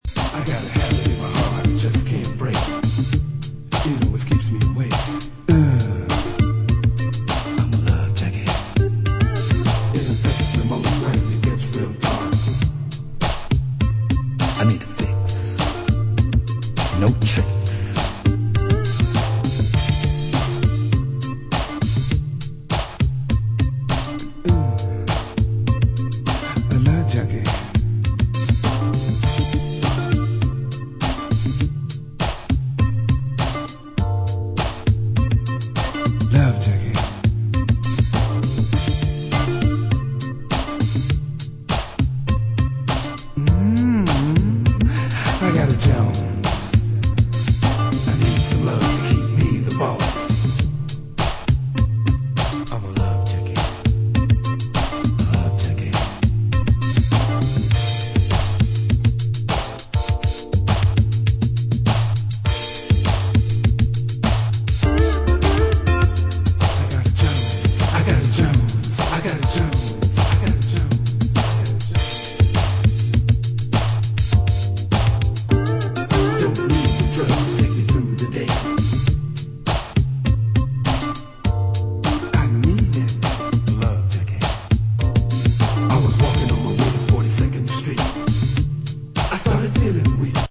Late 80s Florida funk production